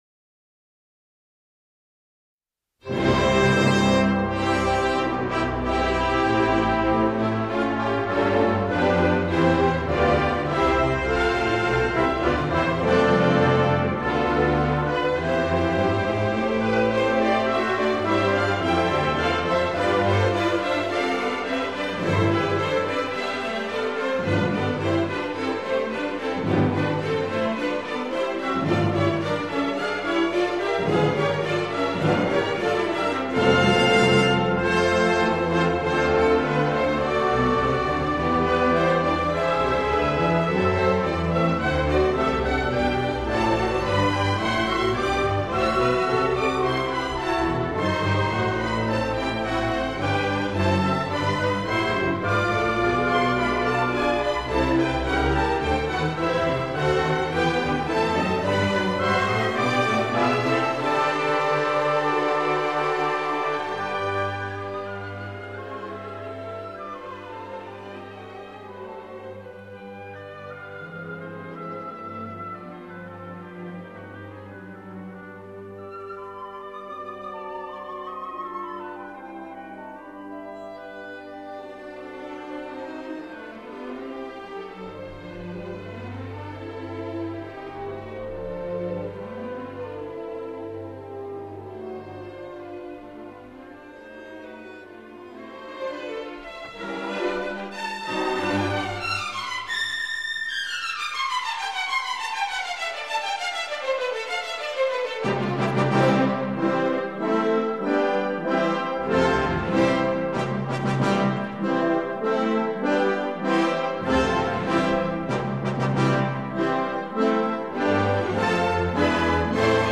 Prelude.